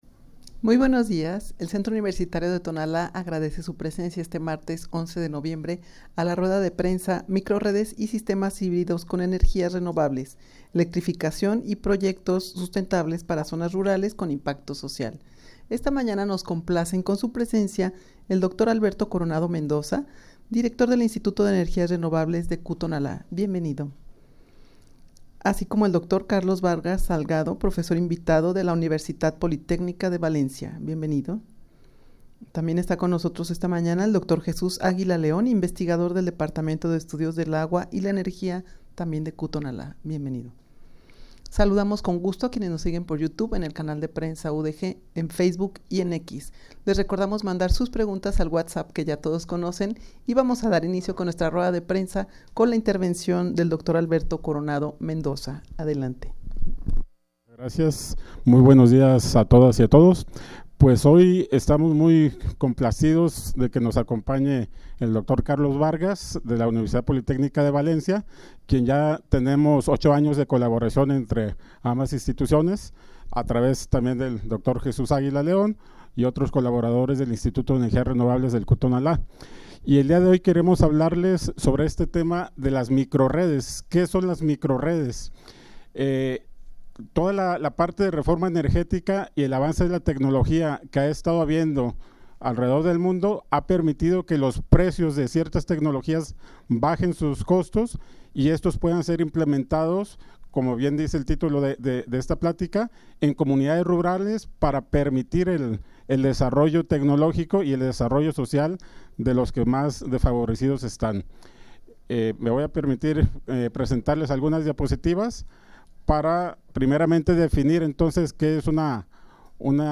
Audio de la Rueda de Prensa
rueda-de-prensa-microrredes-y-sistemas-hibridos-con-energias-renovables.mp3